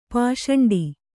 ♪ pāṣaṇḍi